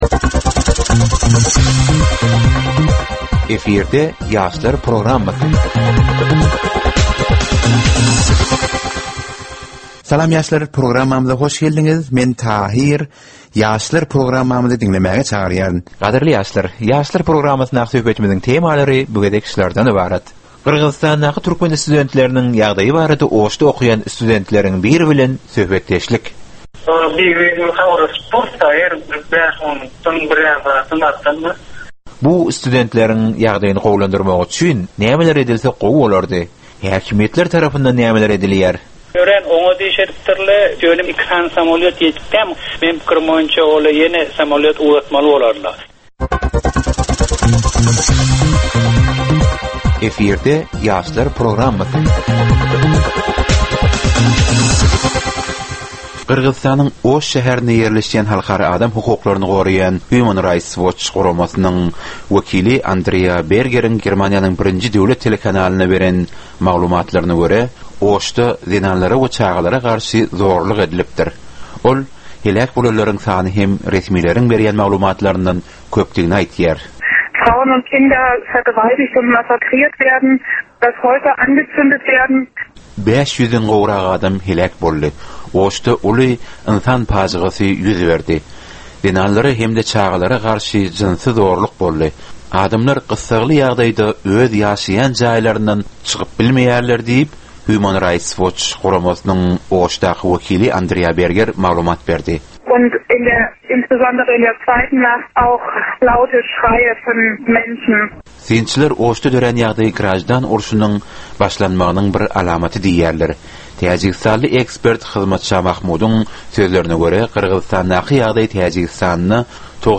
Türkmen we halkara yaşlarynyň durmuşyna degişli derwaýys meselelere we täzeliklere bagyşlanylyp taýýarlanylýan ýörite gepleşik. Bu gepleşikde ýaşlaryň durmuşyna degişli dürli täzelikler we derwaýys meseleler barada maglumatlar, synlar, bu meseleler boýunça adaty ýaşlaryň, synçylaryň we bilermenleriň pikirleri, teklipleri we diskussiýalary berilýär. Gepleşigiň dowamynda aýdym-sazlar hem eşitdirilýär.